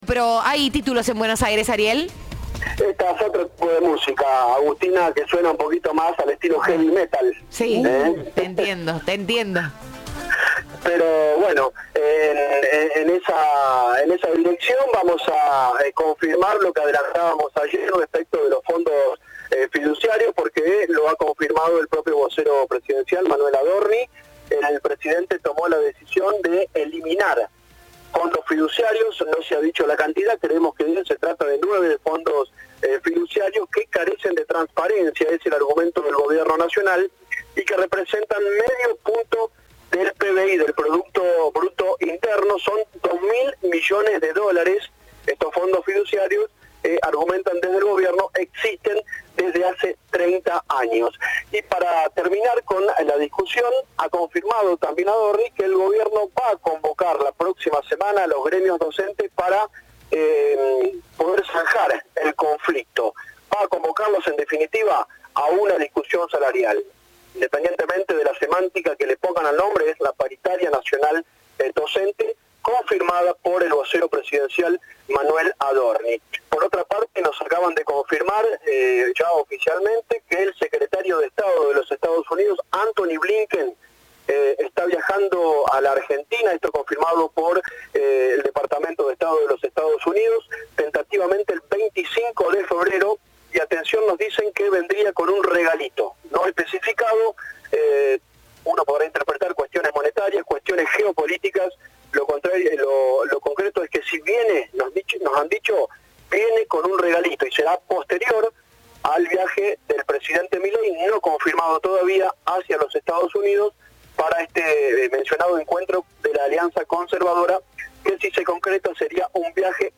En conferencia de prensa en Casa Rosada, Adorni pidió que "la política comprenda que la transparencia tiene que estar por encima de todo".
Informe